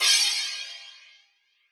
crash 2.wav